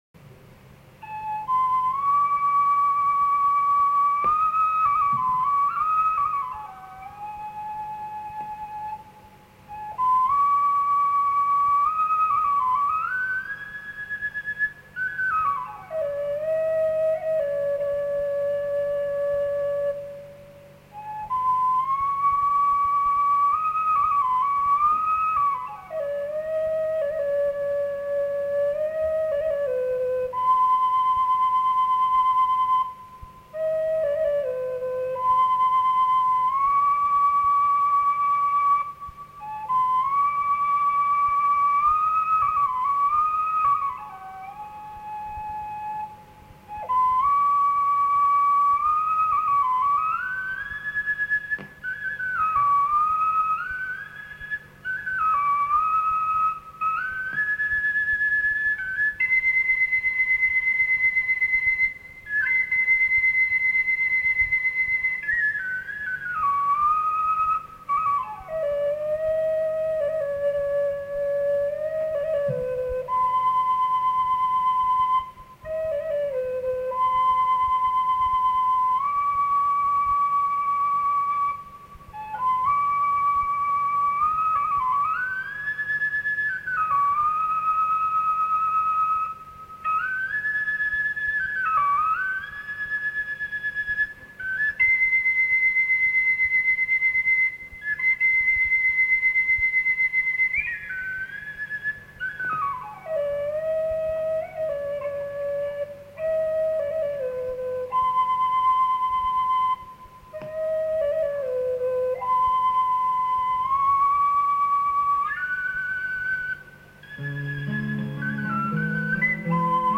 The recording quality was probably never very good and has inevitably deteriorated over the years but still gives an idea of the nature of the material and the approach to its performance.
Flute
Mandolin
Lead Vocals and Guitar